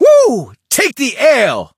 brock_kill_vo_01.ogg